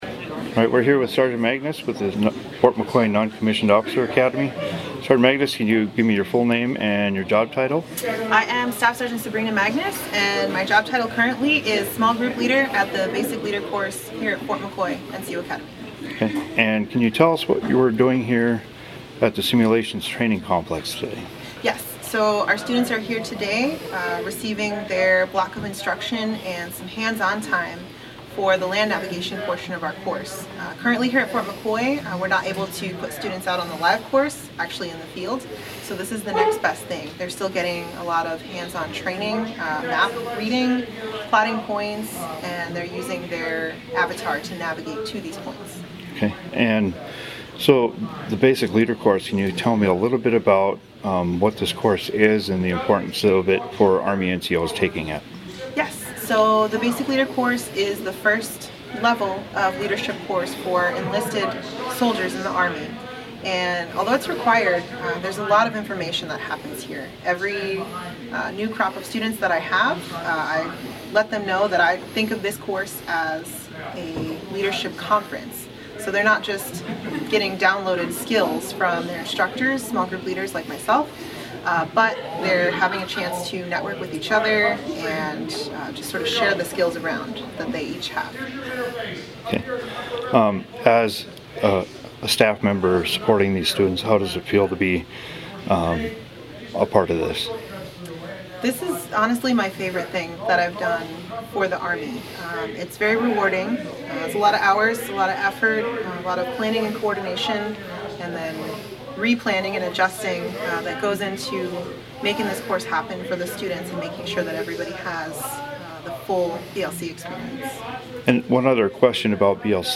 Fort McCoy NCO Academy instructor discusses land-navigation training, Basic Leader Course, academy